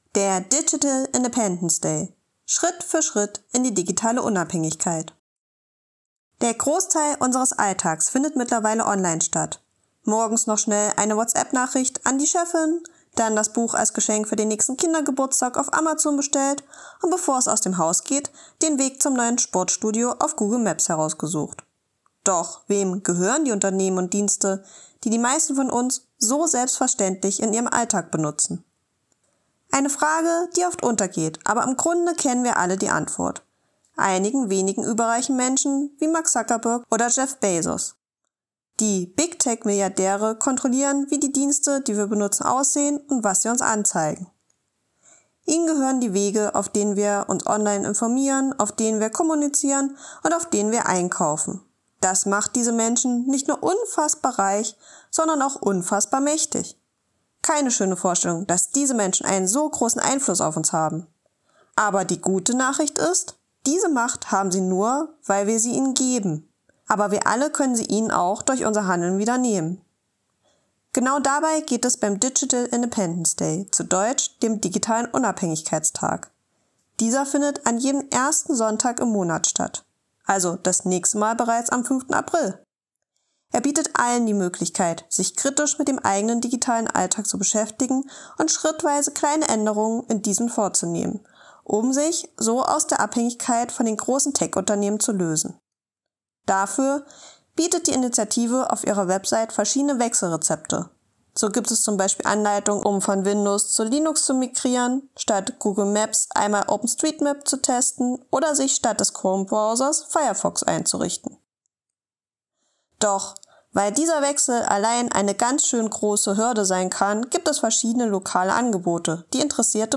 Text als Hörfassung: ▶